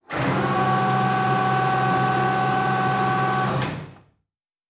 Sci-Fi Sounds / Mechanical / Servo Big 2_2.wav
Servo Big 2_2.wav